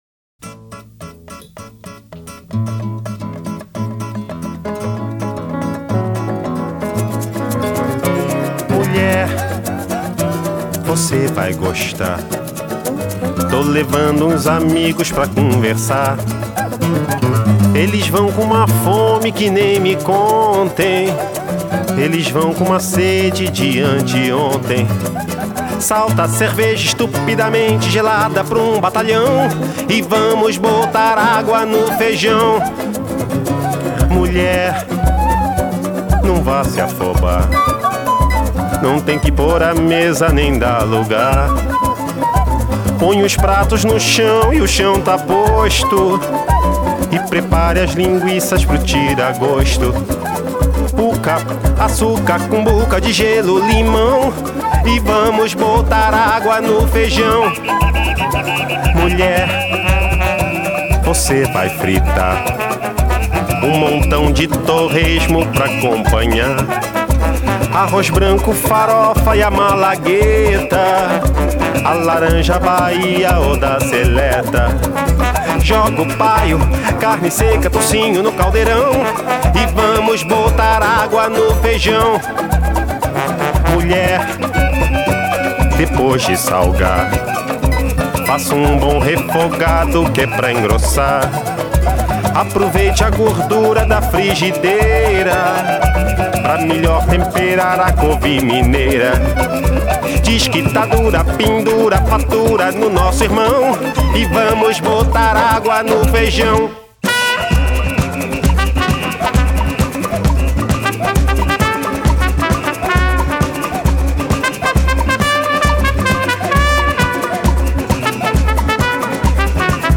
Canto
Música popular